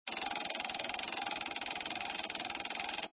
minislot_scrolling.mp3